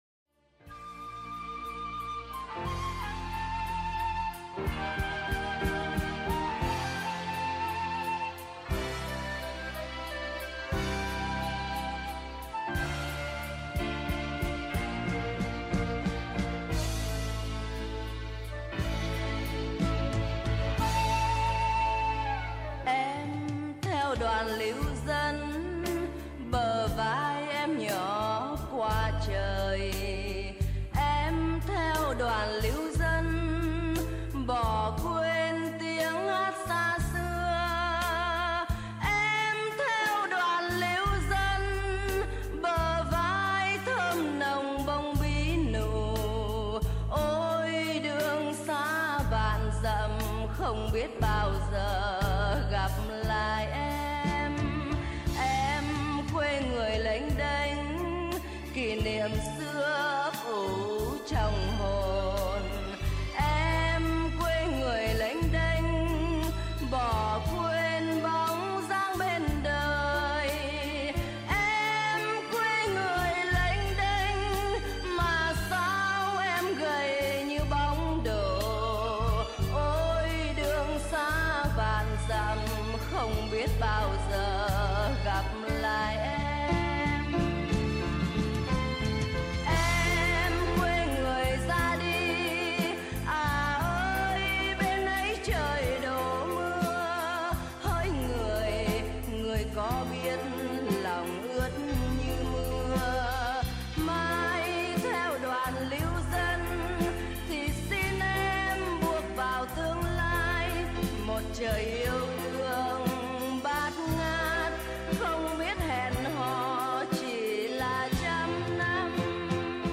Những bản nhạc được VNCT gửi đến khàn giả hôm nay như để nhắc nhớ đến người yêu nhạc ký ức về ngày tháng tư năm bảy lăm lịch sử đã khắc ghi mãi trong tâm tưởng của người miền Nam, nhất là những người đã bỏ nước ra đi vào thời điểm đó.